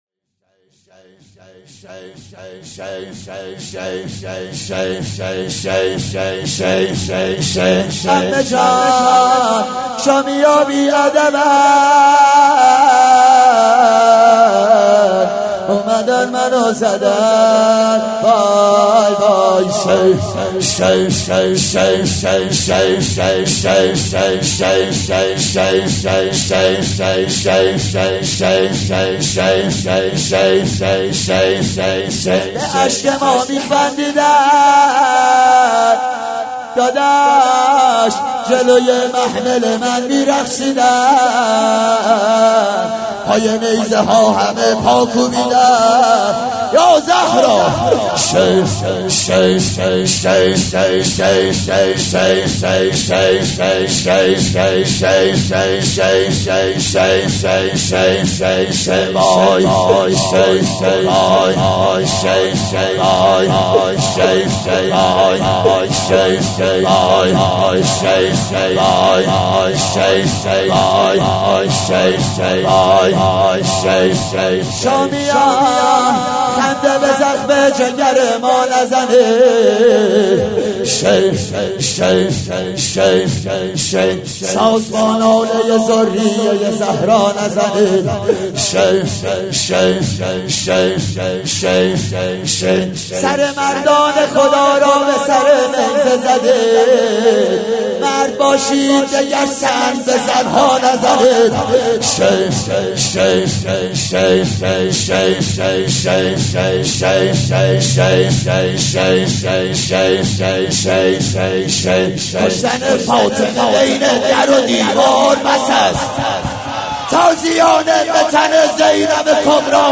04.sineh zani.mp3